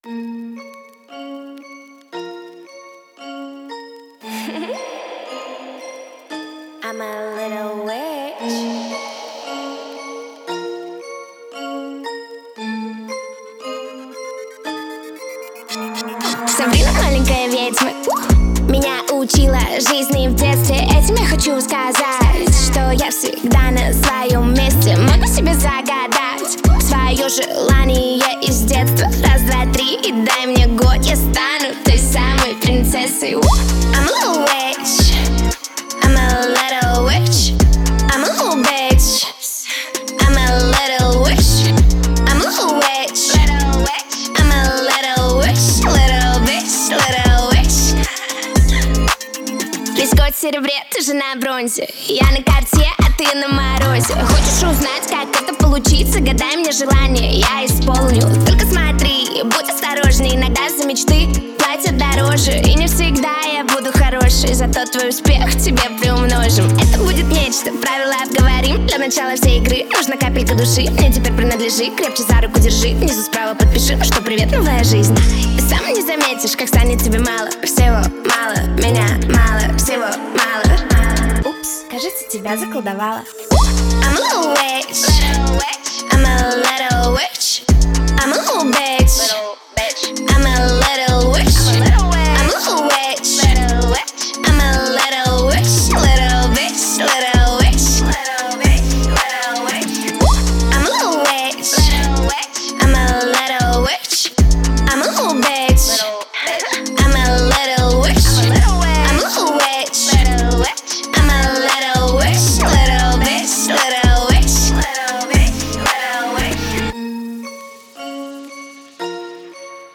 это завораживающая песня в жанре инди-поп